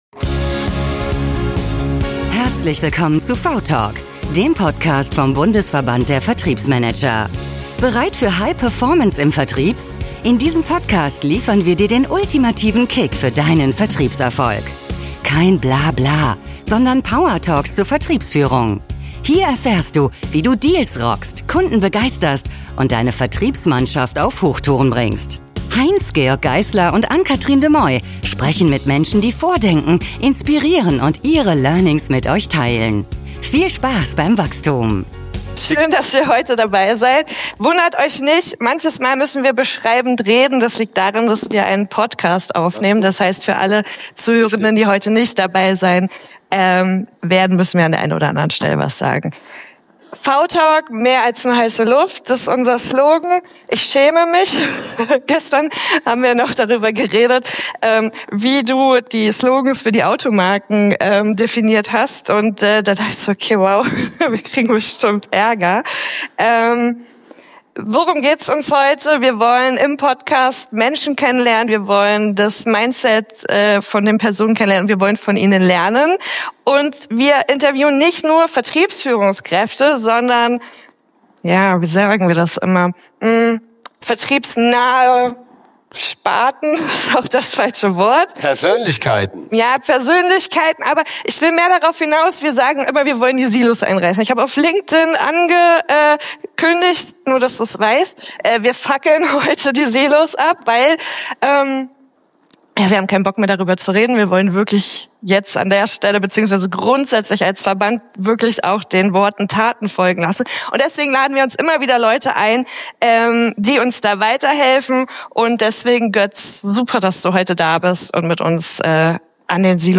Diese Folge sprengt Silos – live und ohne Rücksicht auf Buzzword-Bullshit!